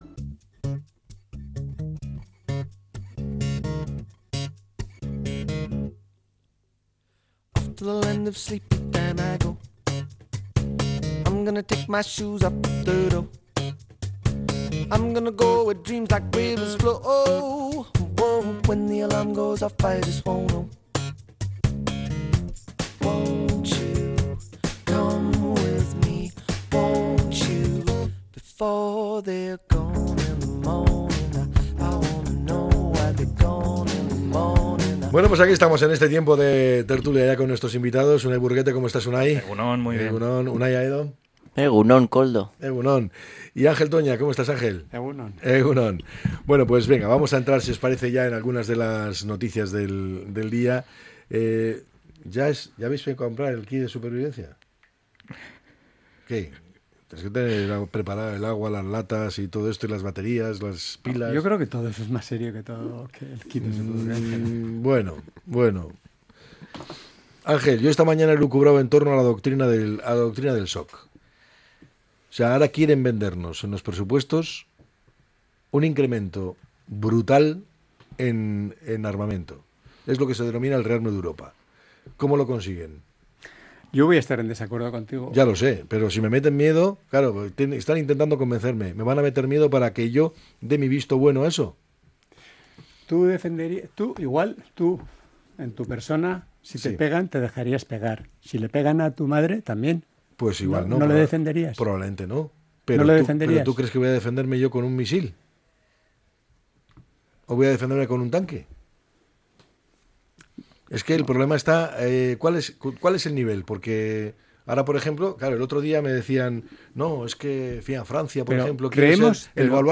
La tertulia 26-03-25.